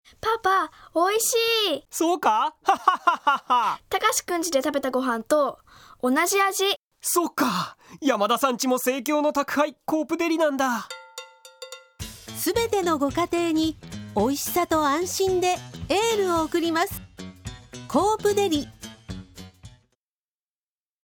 「まじめCM」